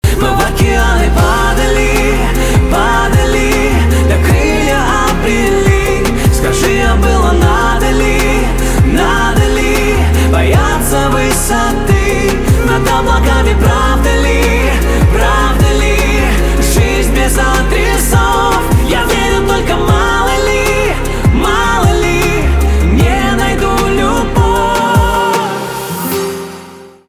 поп
чувственные , красивые , битовые , гитара , грустные